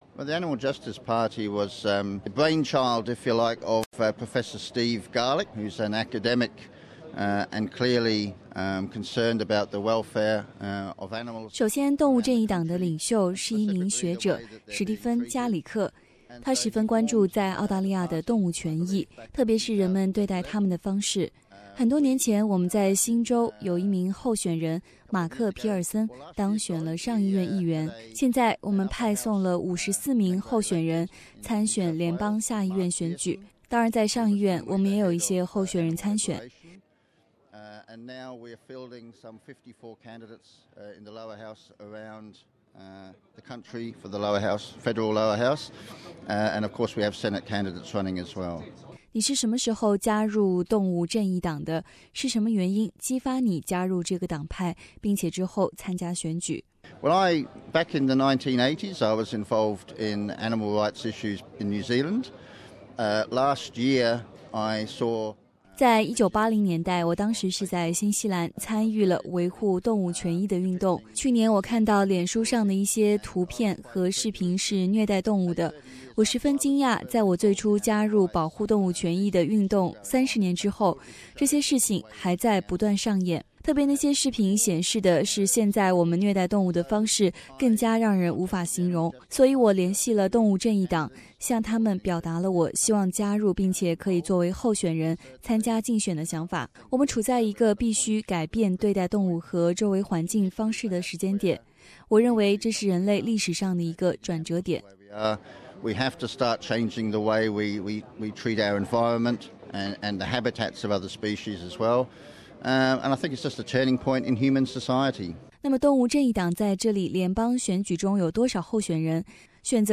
在上周CHATSWOOD普通话广播的大选采访活动中